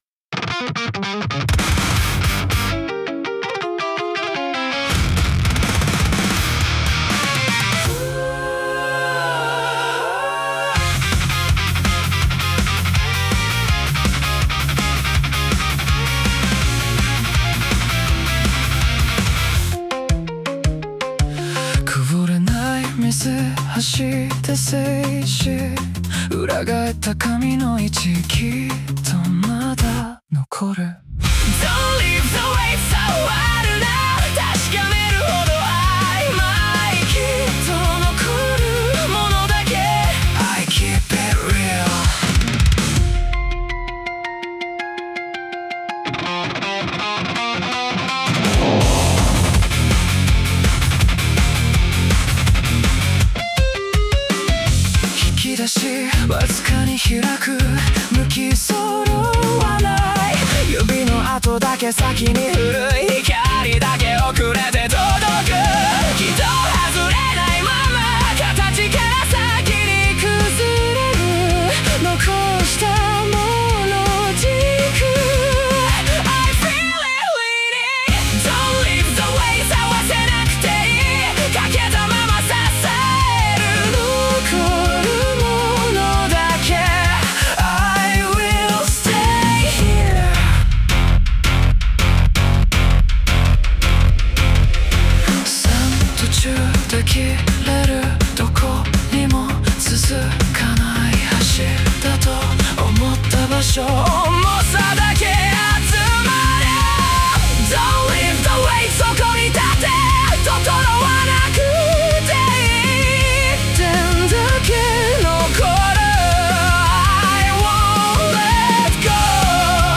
男性ボーカル
イメージ：メタルコア,ダークオルタナティブ,エレクトロニックメタル,エクスペリメンタルロック,男性ボーカル